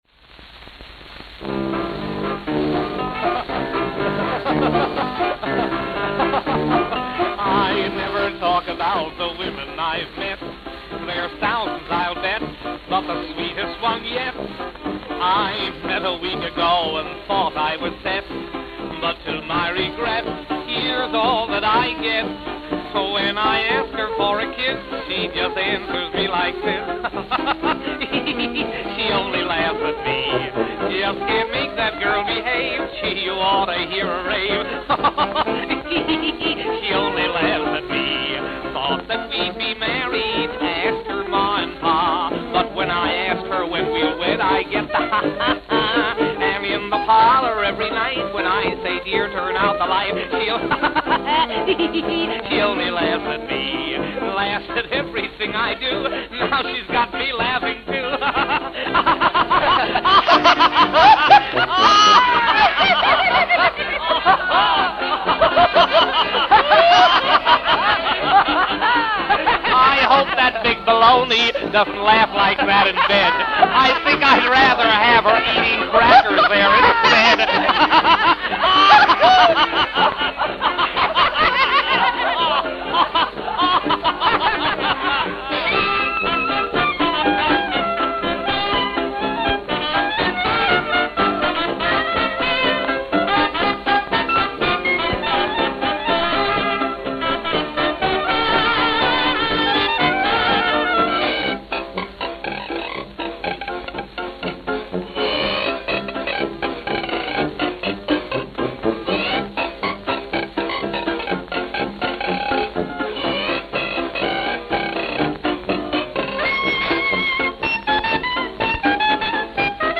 Note: Worn.